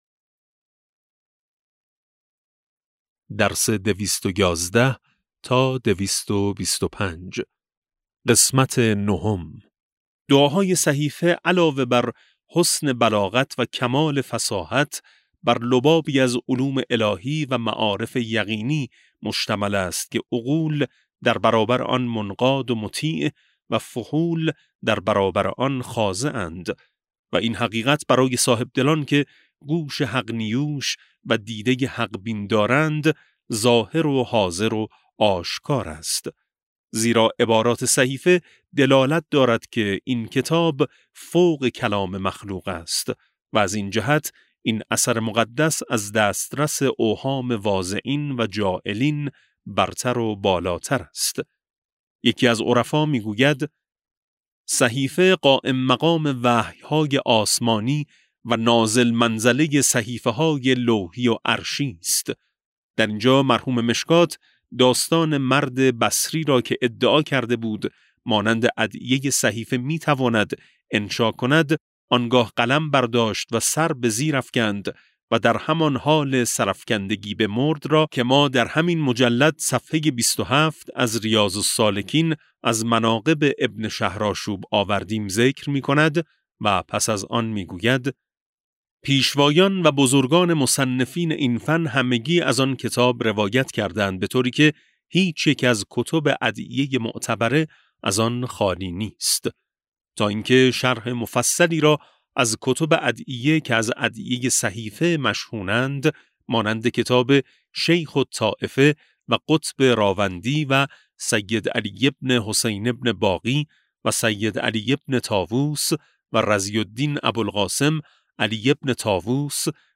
کتاب صوتی امام شناسی ج15 - جلسه9